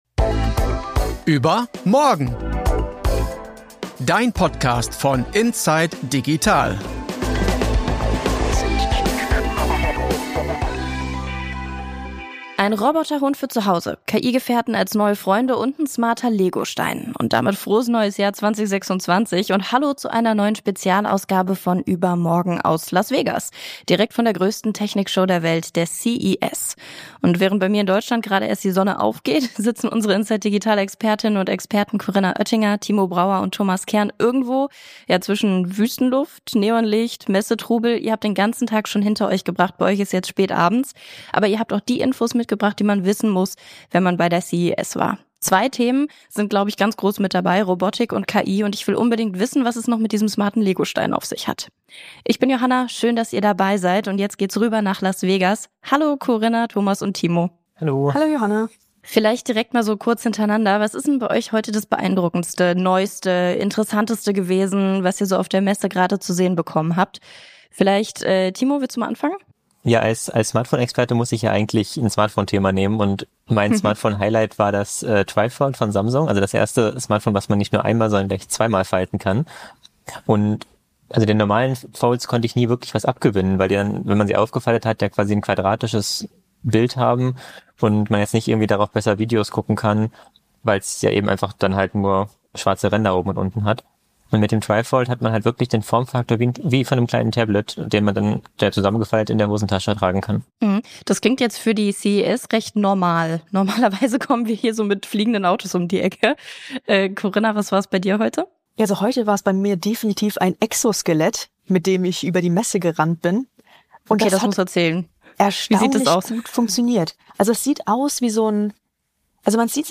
Unsere Expertinnen und Experten berichten direkt von der Messe über die spannendsten Highlights, sinnvolle Innovationen - und Entwicklungen, die kritisch hinterfragt werden müssen.